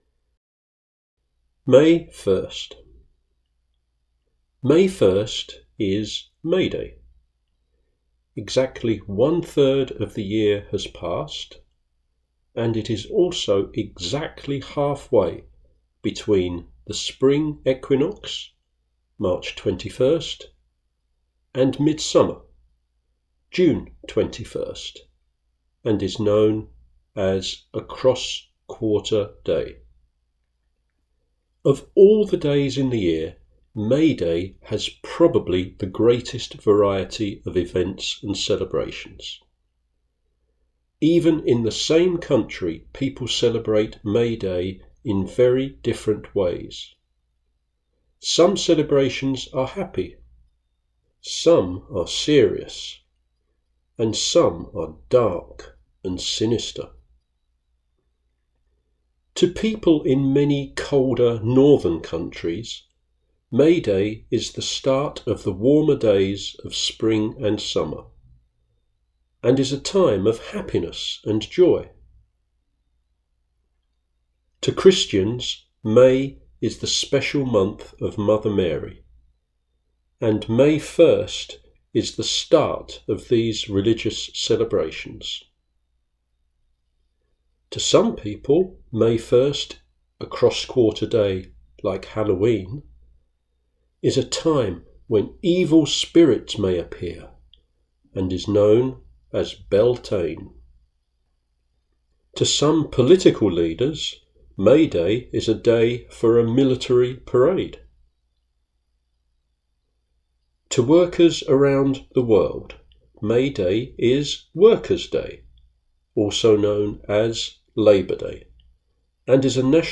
May 1st audio narration